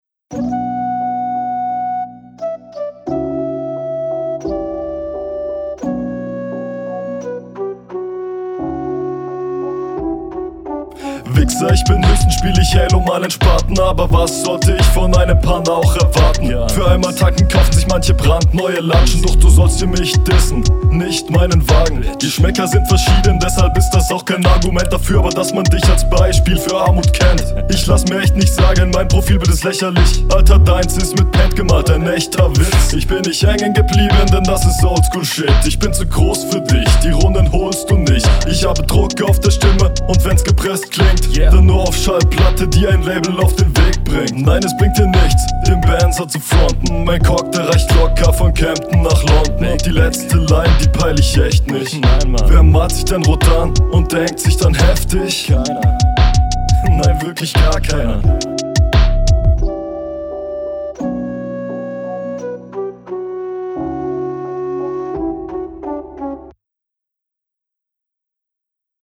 Sound und Flow: Mix ist gut.
Was mir hier direkt auffällt ist der Stimmeinsatz, der viel angenehmer und abwechslungreicher ist als …